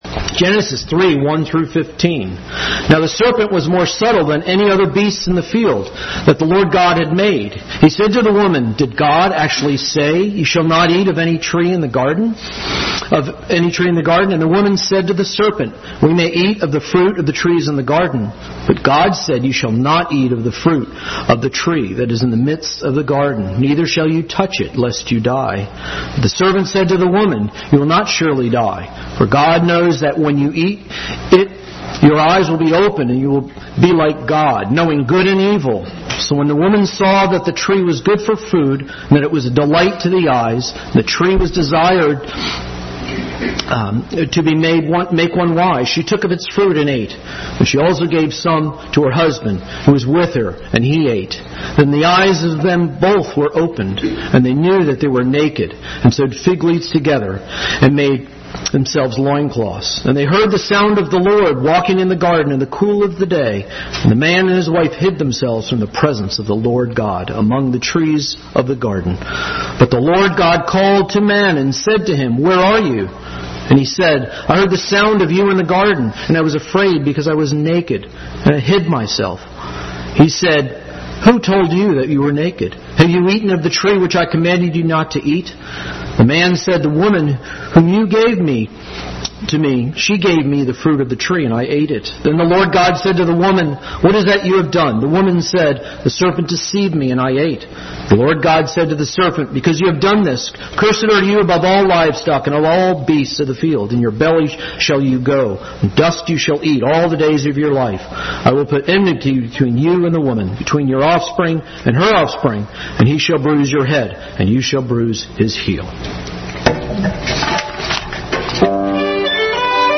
Bible Text: Various | 2019 Christmas Program with special music and readings.